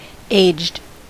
Ääntäminen
IPA : /eɪdʒd/ US : IPA : [eɪdʒd]